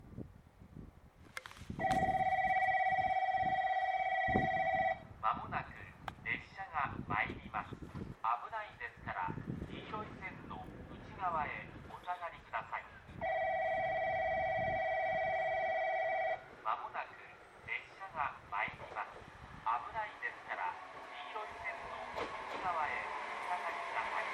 この駅では接近放送が設置されています。
１番のりば日豊本線
接近放送普通　宮崎行き接近放送です。